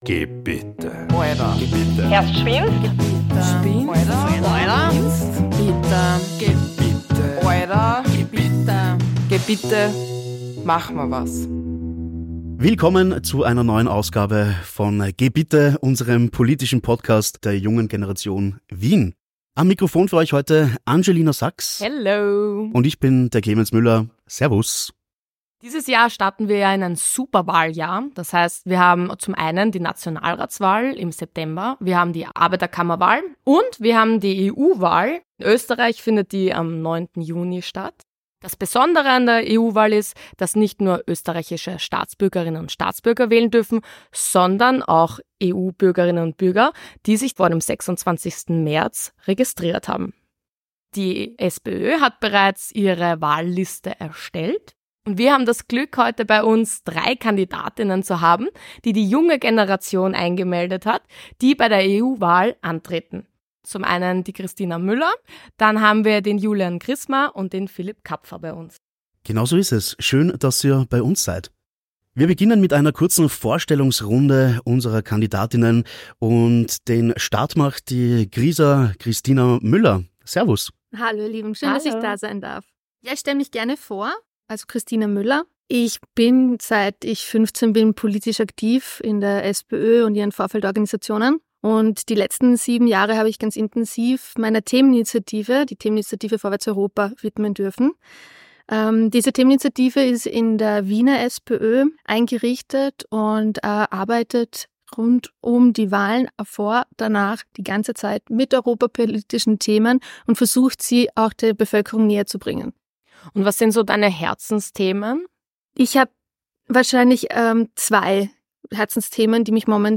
Europawahl: EU Kandidat*innen beantworten eure Fragen (Q&A) ~ GEH BITTE! - Mach' ma was! Podcast